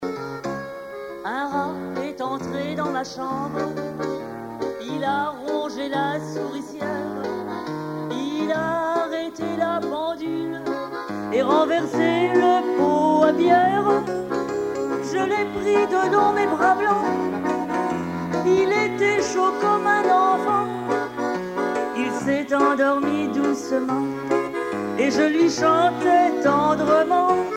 Genre strophique
Chansons de la soirée douarneniste 88
Pièce musicale inédite